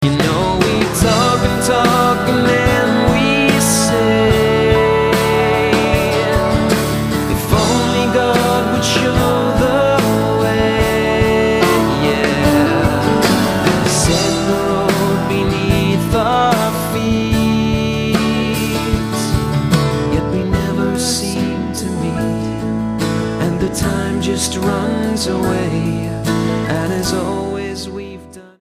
STYLE: Pop
It's all very pleasant but unfortunately not very memorable.